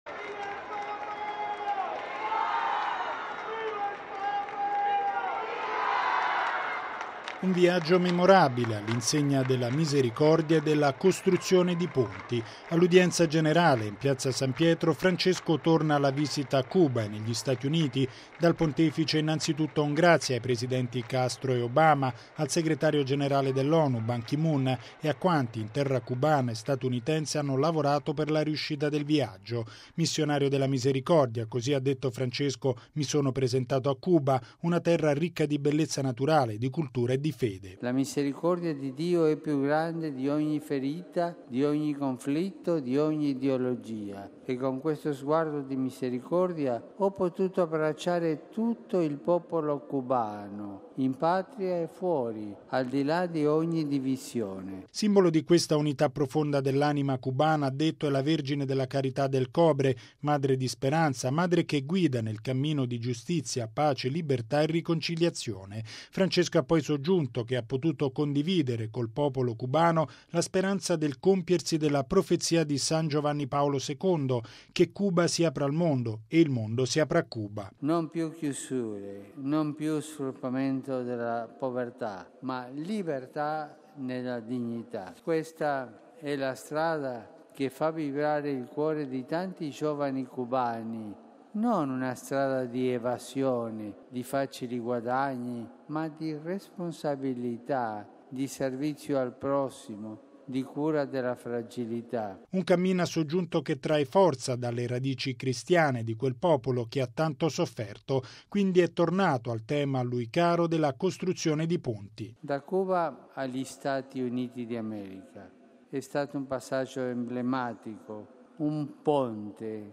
E’ quanto affermato da Papa Francesco all’Udienza generale in Piazza San Pietro, tutta dedicata al viaggio apostolico a Cuba e negli Stati Uniti. Il Pontefice ha sottolineato che la misericordia di Dio è più forte dei conflitti e delle ideologie ed è tornato a mettere l’accento sull’importanza della famiglia, a pochi giorni dall’inizio del Sinodo dei vescovi.